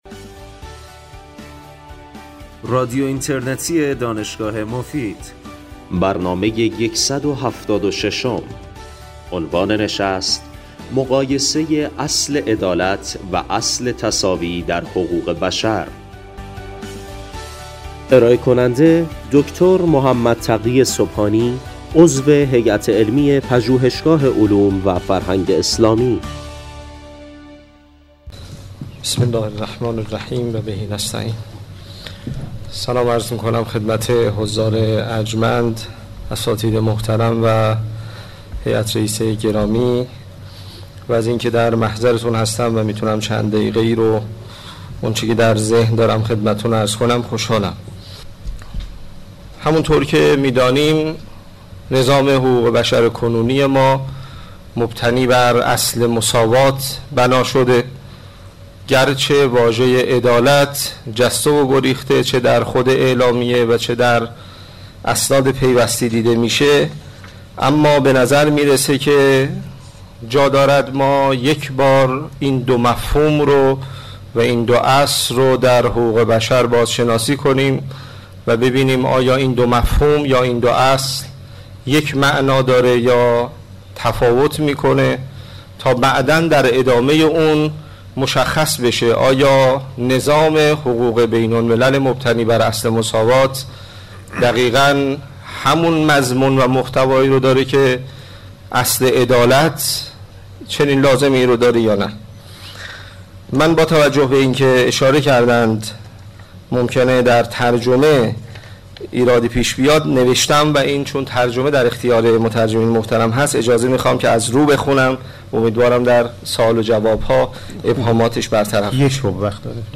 در این سخنرانی که در سال ۱۳۸۶ و در چهارمین همایش بین المللی حقوق بشر دانشگاه مفید ایراد نموده اند
همچنین ایشان اظهار میدارند که در دین اسلام، اصل، عدالت است و مساوات را باید در چارچوب عدالت فهم نمود. در پایان برنامه نیز سوالات و نقدهایی از سوی حضار مطرح می‌گردد